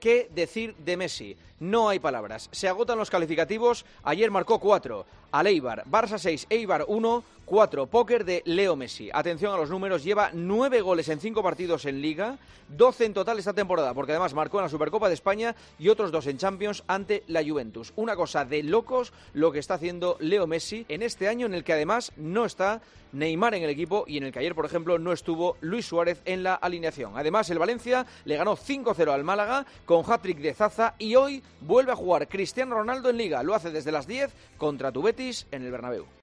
El Barcelona arrolla al Eibar con cuatro goles de Messi, en el comentario de Juanma Castaño director de 'El Partidazo de COPE', en 'Herrera en COPE'.